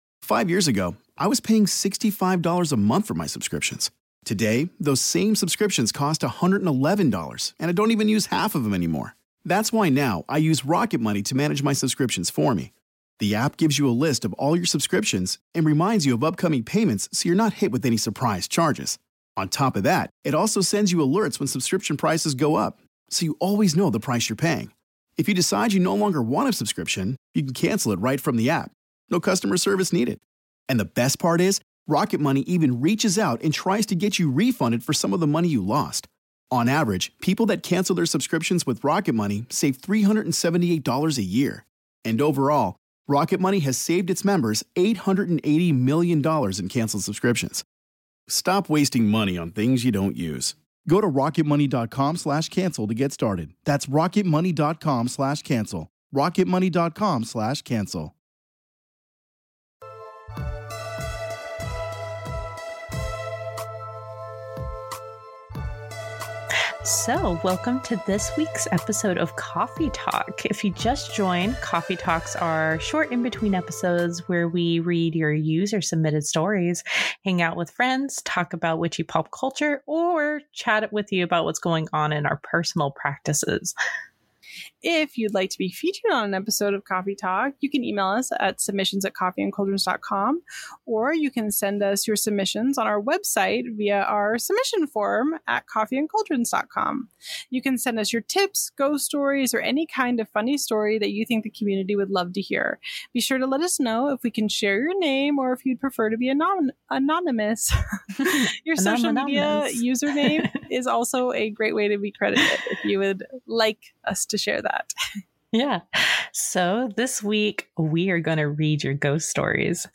These are fun in-between episodes where we read your user-submitted stories, hang out with friends, talk about witchy pop culture, or chat with you about what's going on in our personal practices! This week we read your Ghost Stories!